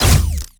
Added more sound effects.
GUNAuto_Plasmid Machinegun C Single_01_SFRMS_SCIWPNS.wav